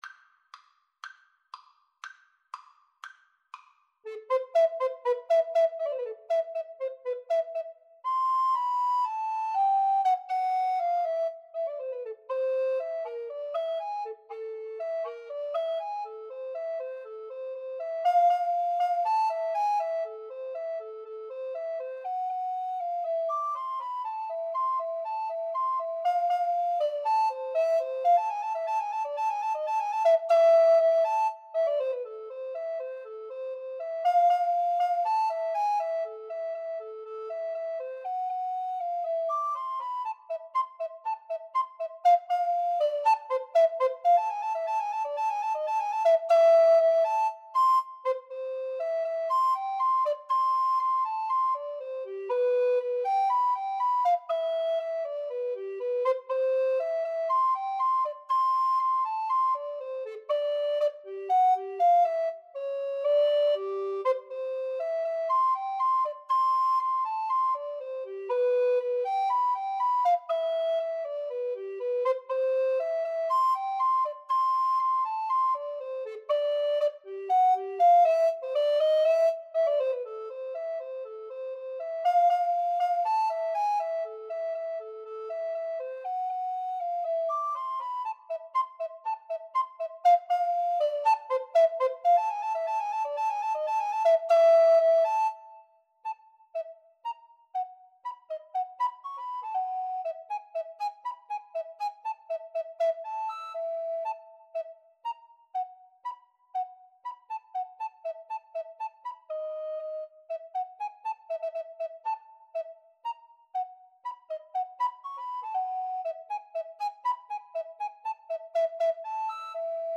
Soprano RecorderAlto Recorder
Allegretto Misterioso = 120
2/4 (View more 2/4 Music)
Classical (View more Classical Recorder Duet Music)